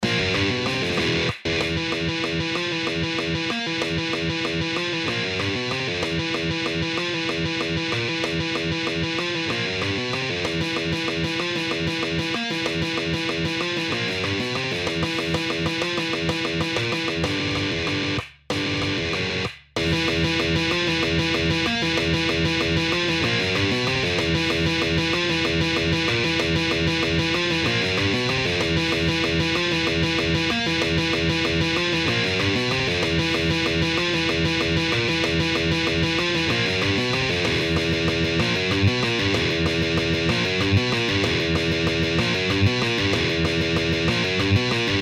The song features one of most memorable james hetfield downpickings, which drives the song’s intense, apocalyptic feel.